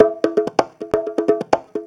Conga Loop 128 BPM (10).wav